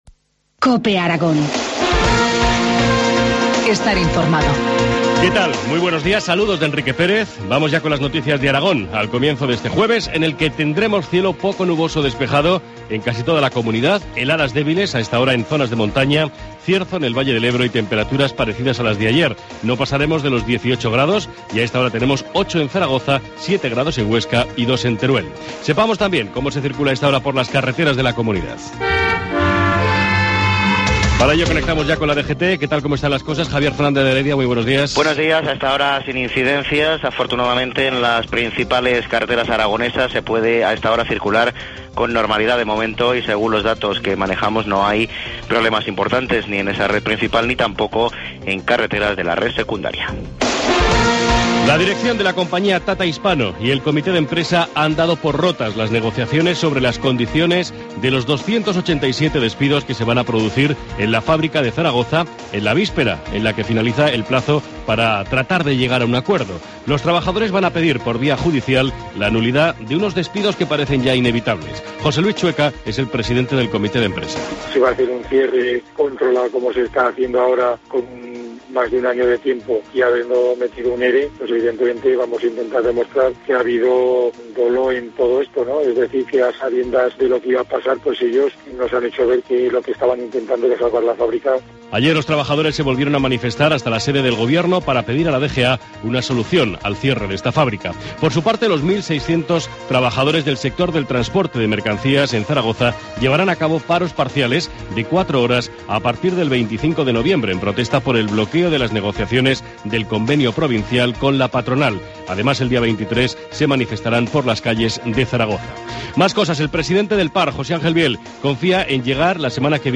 Informativo matinal, jueves 31 de octubre, 7.25 horas